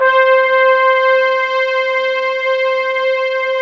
ORCHEST.C5-L.wav